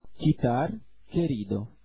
qu k (